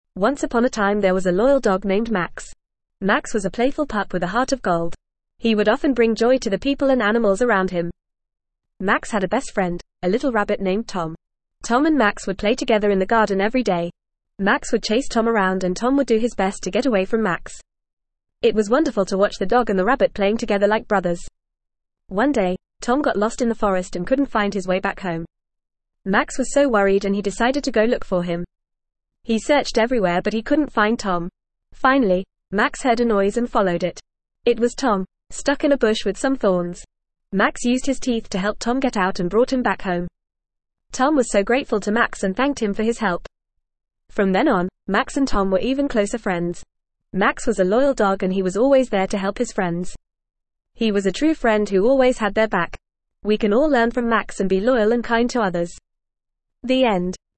Fast
ESL-Short-Stories-for-Kids-FAST-reading-The-Loyal-Dog.mp3